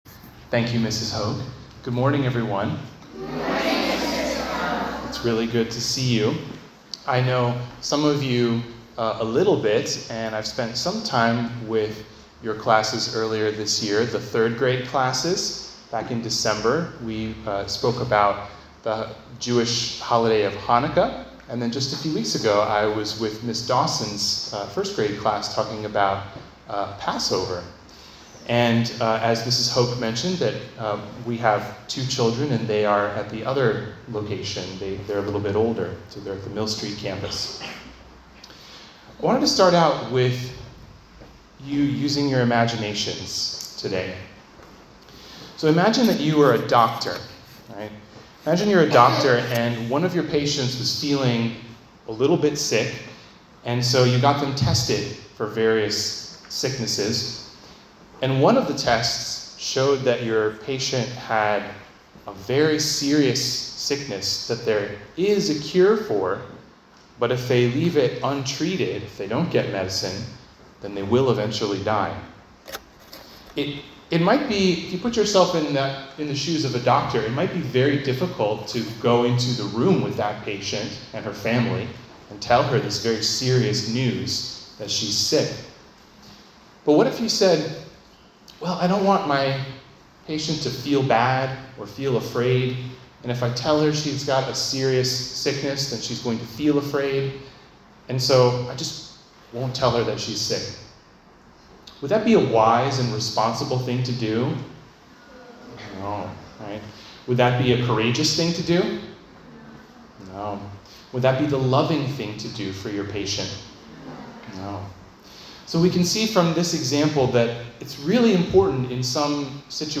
Sermon: Eternal Separation from God
This is the audio (14:53, 13.7 MB) of a sermon I preached at the Madison Avenue (elementary) campus of Grove City Christian Academy on May 2, 2025.